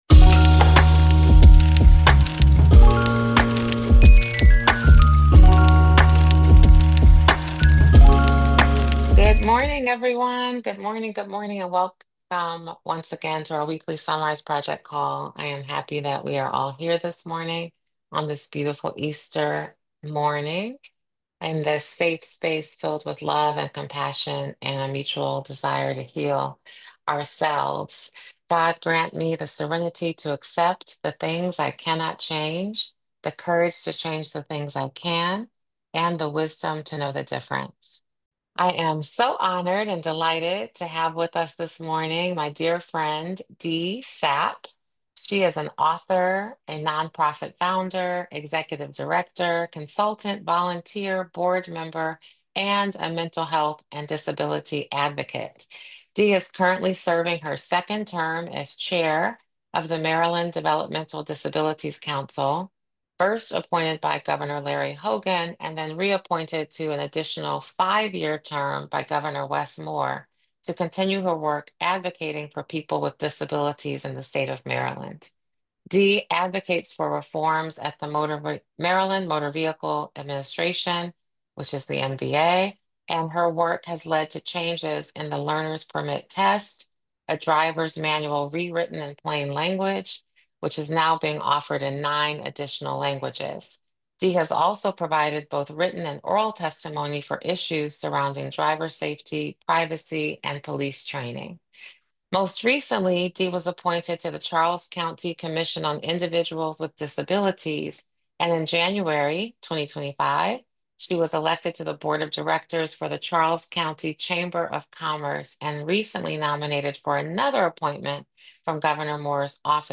open and honest conversation